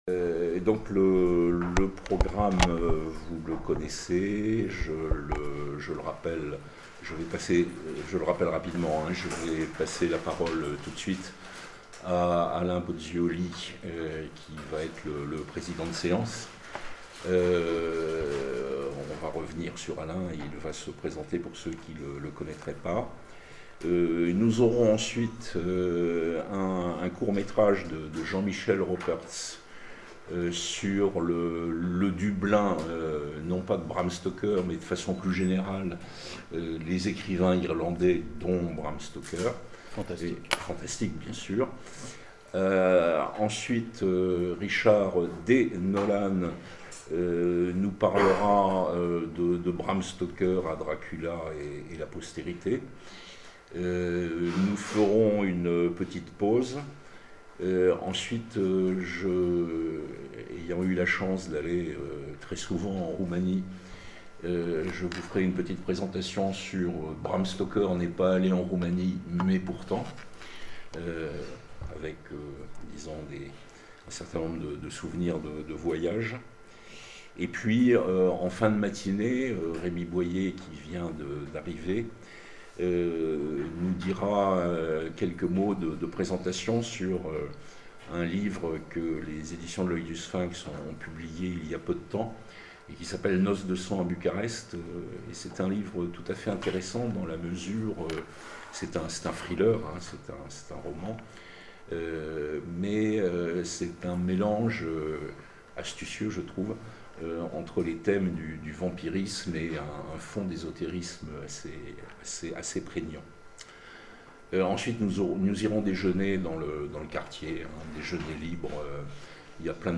Mots-clés Bram Stoker Vampire Conférence Partager cet article